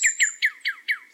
animalia_cardinal.2.ogg